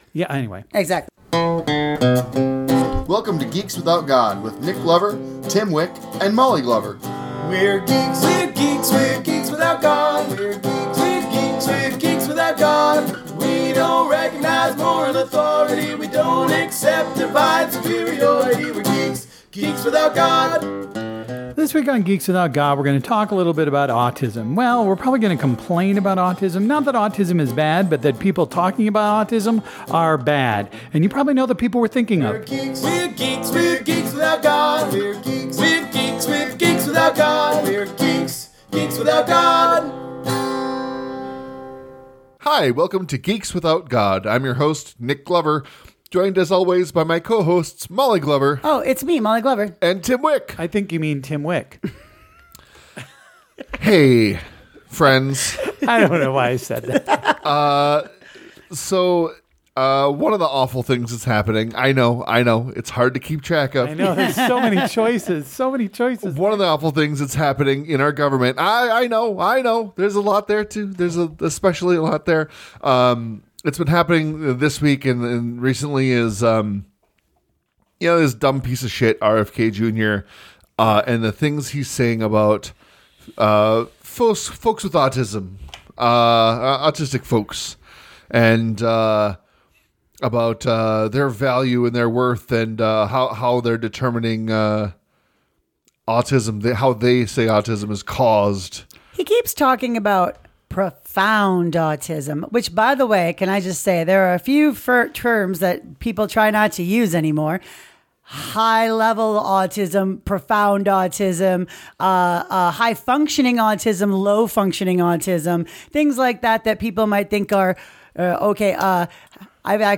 Geeks Without God is a podcast by three atheist/comedian/geeks. We'll talk about geeky stuff, atheist issues and make jokes.